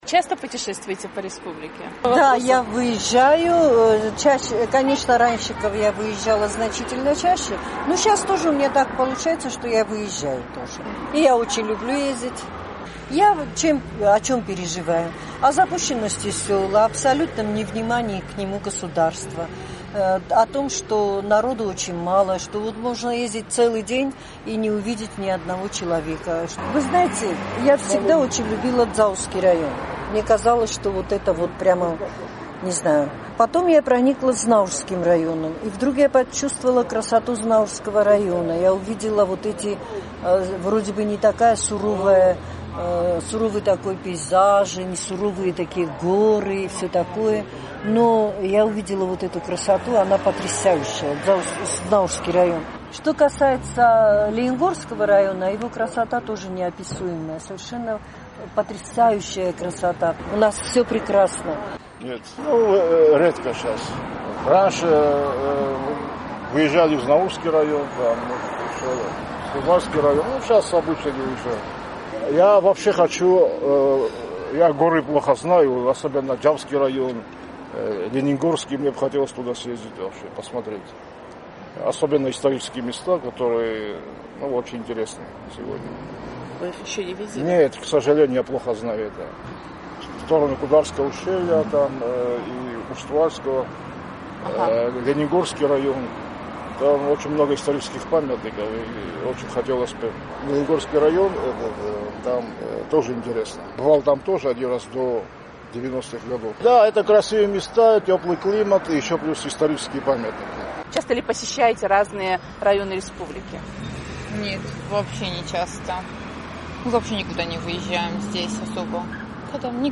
Как часто жители республики путешествуют по Южной Осетии и есть ли у них любимые места? «Эхо Кавказа» спросило об этом жителей Цхинвала.